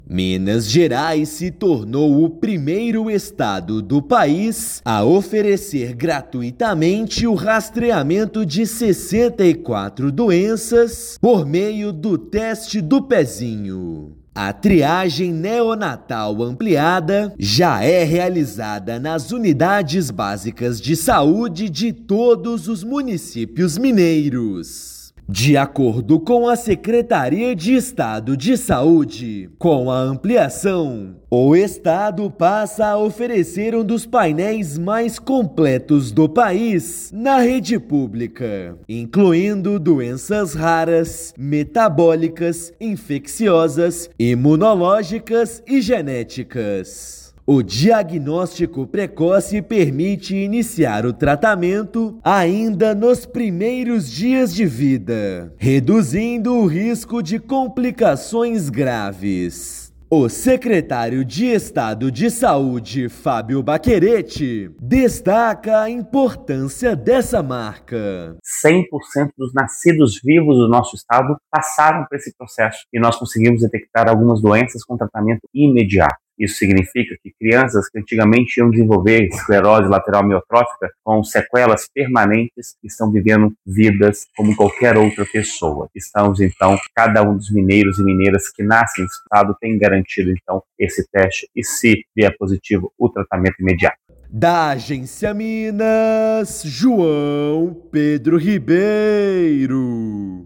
Estado sai na frente com exame ampliado disponível gratuitamente nos 853 municípios, antecipando a lei federal e garantindo tratamento rápido para recém-nascidos. Ouça matéria de rádio.